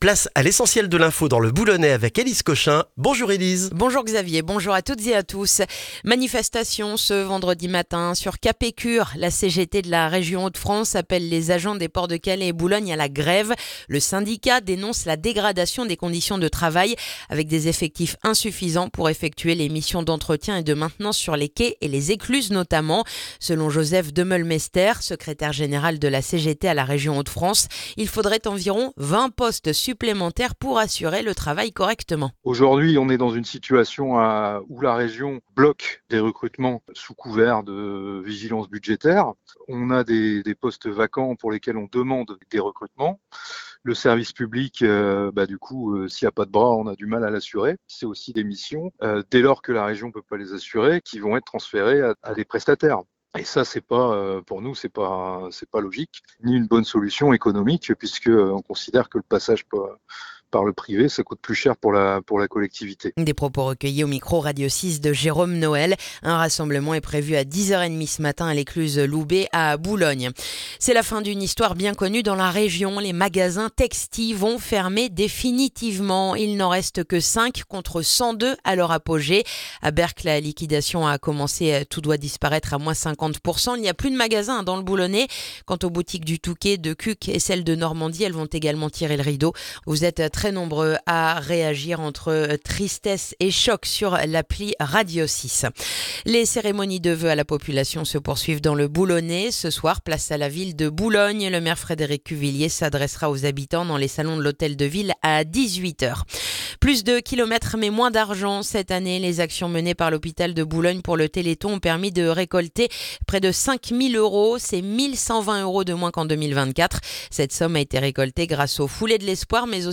Le journal du vendredi 16 janvier dans le boulonnais